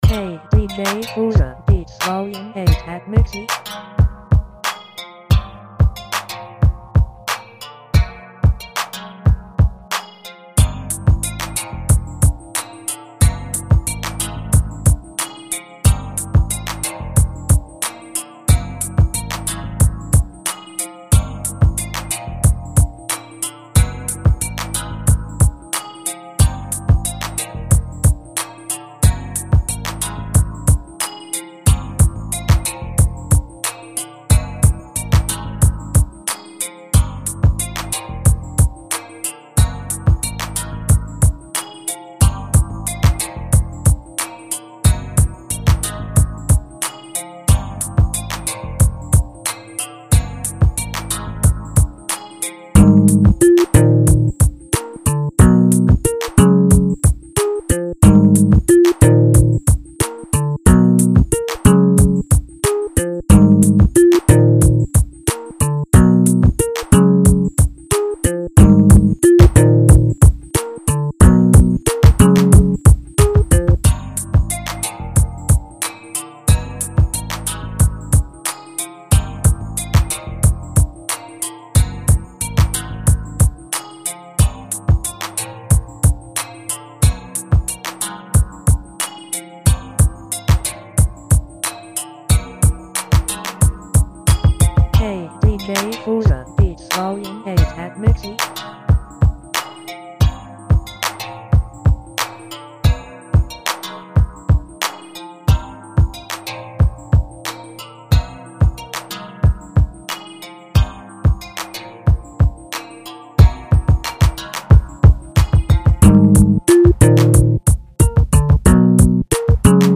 ラテン調のギターメロに落としたベースラインとサンプルから適当にチョイスした内臓音源のみで制作。